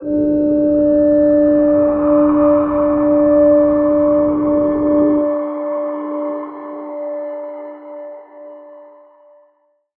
G_Crystal-D6-mf.wav